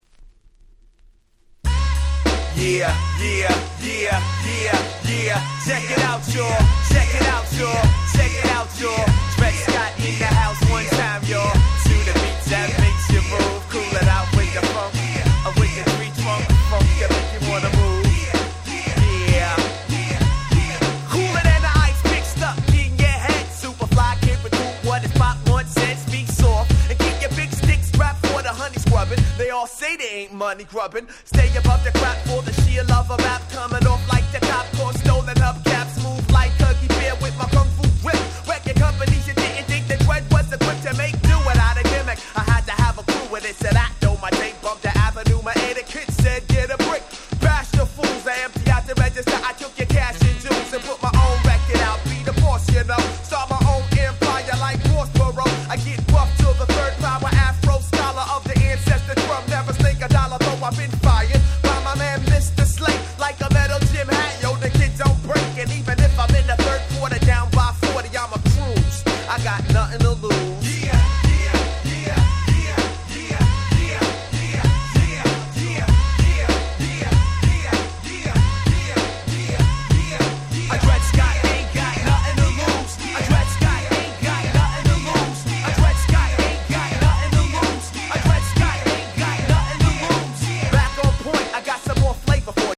93’ Nice Hip Hop !!